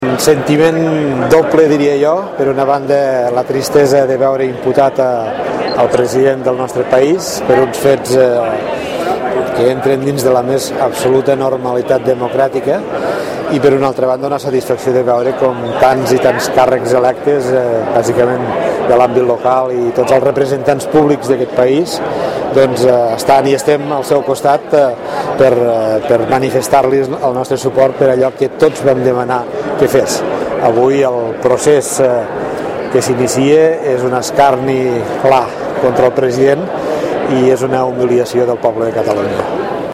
El president de la Diputació de Lleida ha participat a la concentració del món local per donar suport al president Mas
En el transcurs de la concentració el president de la Diputació de Lleida, Joan Reñé, ha manifestat als mitjans de comunicació que té un doble sentiment.
Re--e_concentracio_suport_Mas.mp3